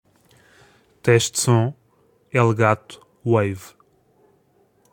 teste-de-som.ogg